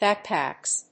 /ˈbæˌkpæks(米国英語)/